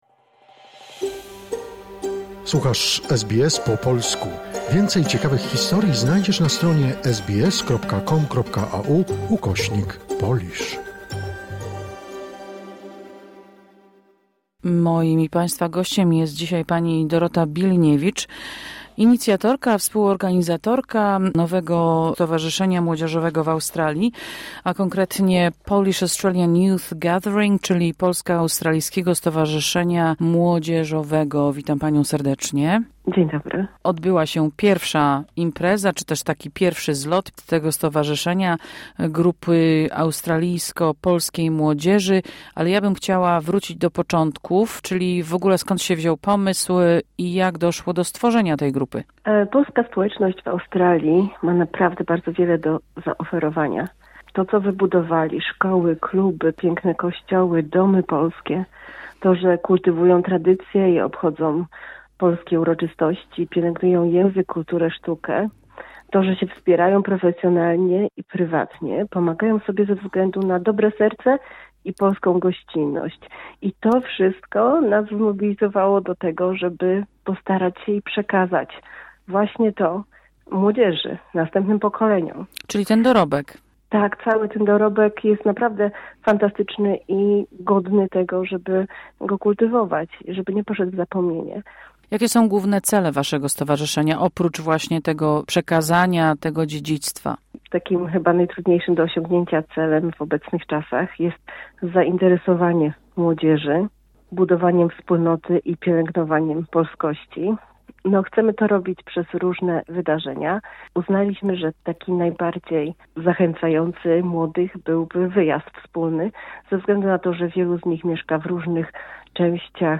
Posłuchaj całej godzinnej audycji radiowej tutaj...